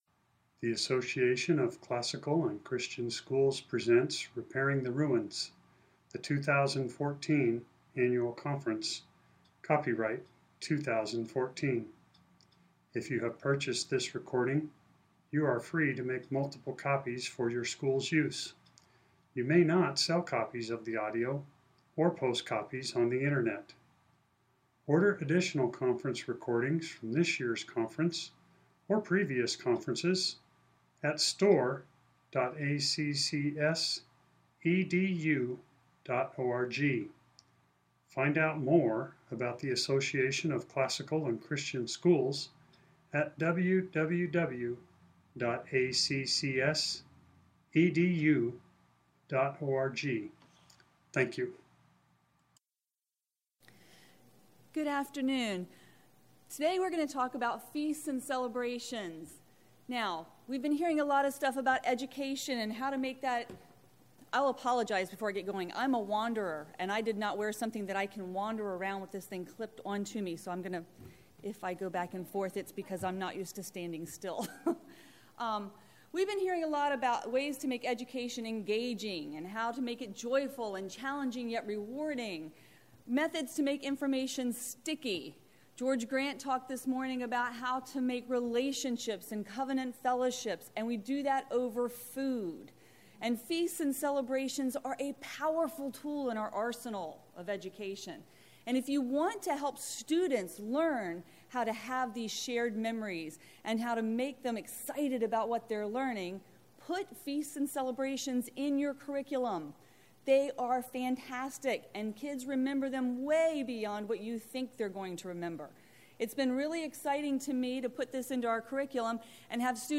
2014 Workshop Talk | 0:59:04 | All Grade Levels